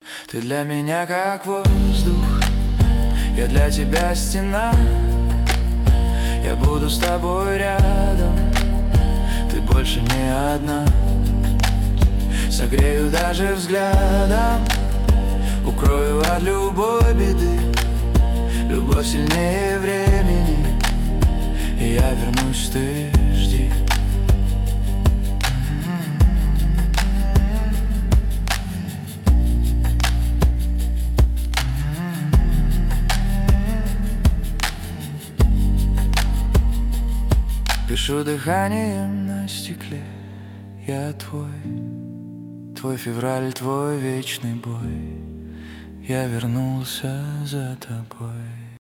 поп , нейросеть